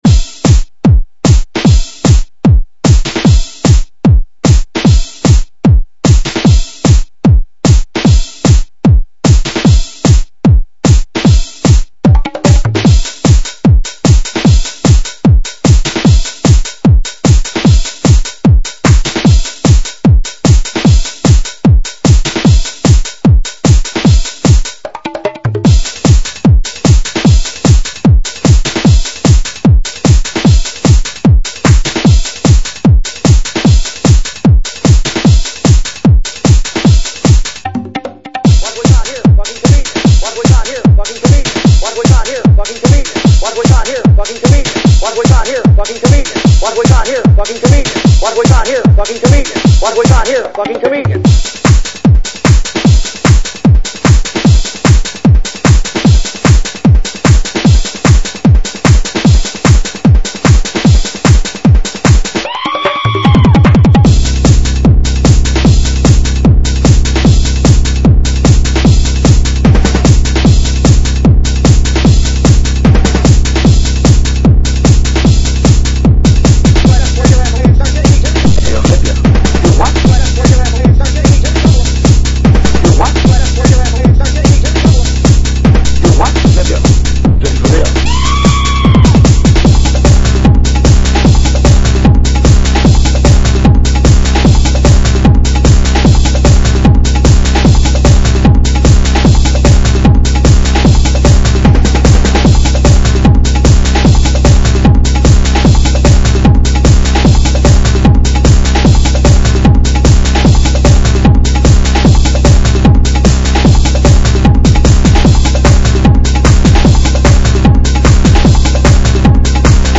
dance/electronic
Hard-house/hi-nrg
Trance